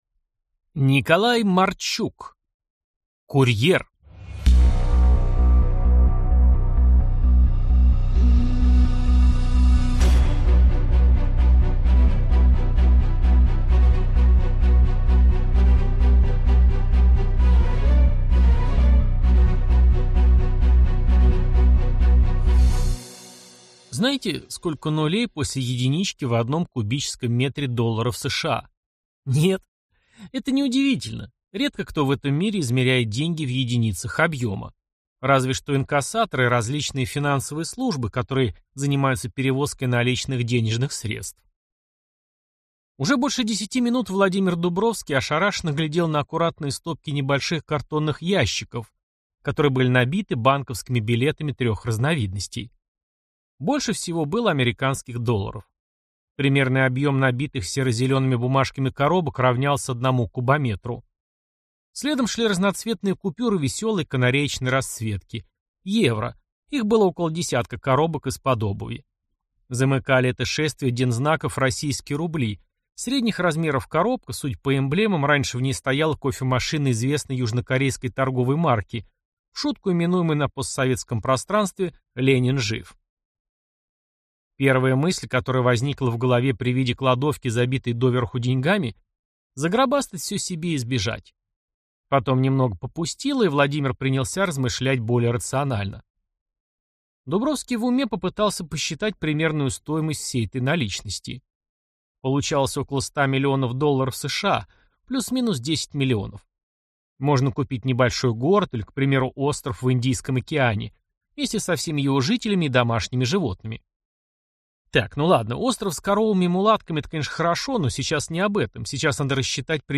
Аудиокнига Закрытый сектор. Курьер | Библиотека аудиокниг